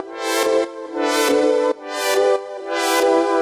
Index of /musicradar/french-house-chillout-samples/140bpm/Instruments
FHC_Pad A_140-A.wav